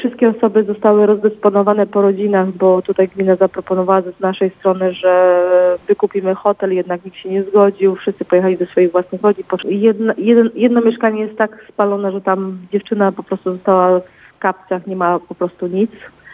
W tzw. pałacyku mieszkało siedem rodzin, dwie ucierpiały najbardziej – mówi wójt gminy Sylwia Kalmus – Samsel.